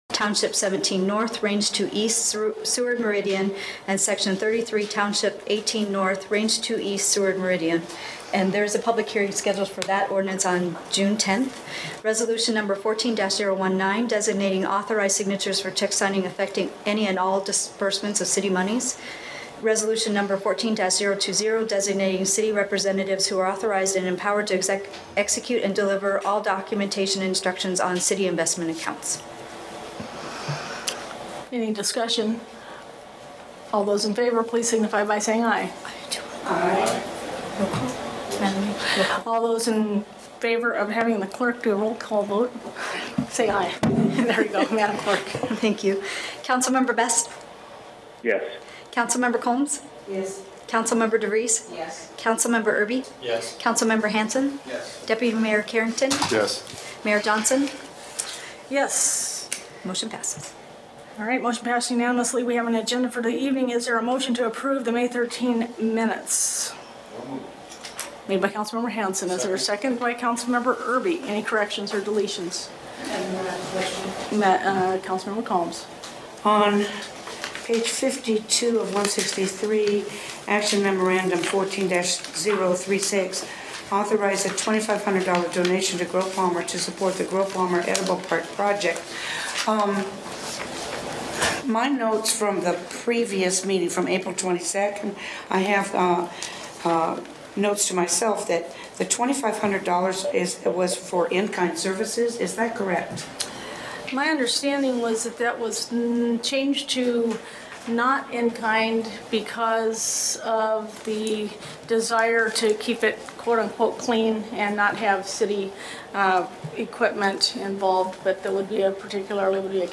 All Council members were present, Council member Best by phone.